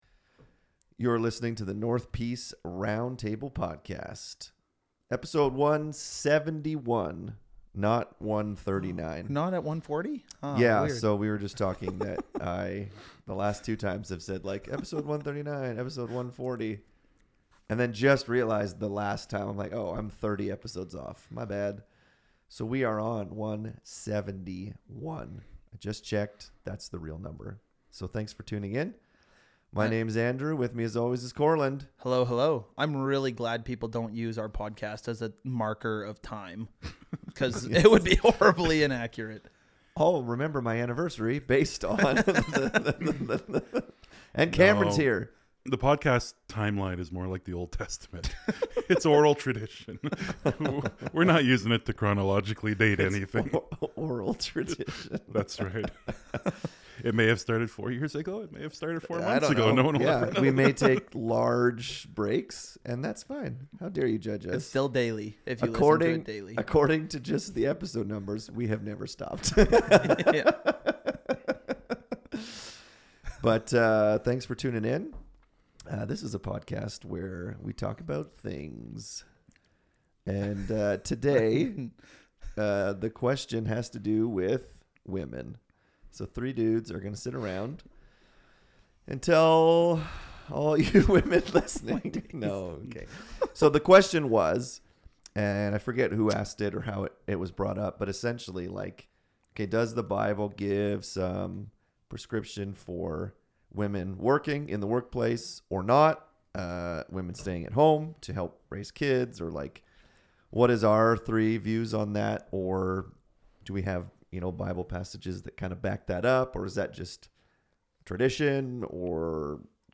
Episode 171 - In this episode the guys talk about the differences between men and women when it comes to working. Does the bible give a prescription for women staying at home and men working? How do we use wisdom in raising our kids and running our families?